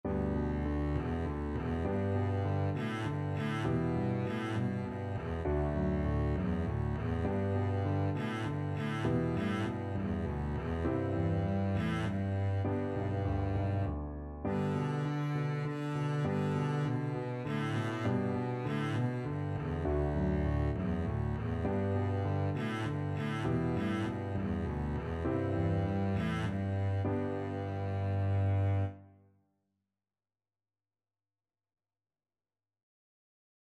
Double Bass version
Korean folk song
3/4 (View more 3/4 Music)
D3-D4